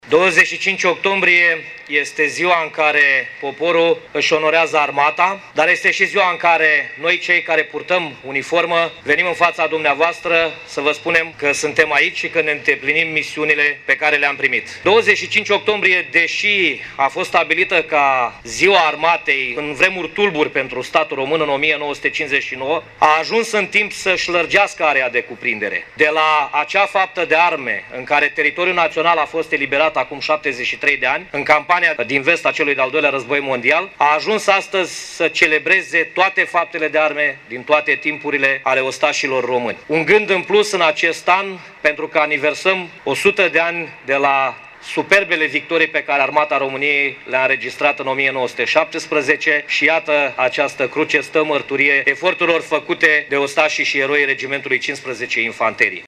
În municipiul Iasi, evenimentele prilejuite de Ziua Armatei României au început la ora 10,00, la Troiţa înălţată în faţa comandamentului Brigăzii 15 Mecanizate „Podu Inalt” de pe Bulevardul Carol I cu o ceremonie militară de depuneri de coroane de flori.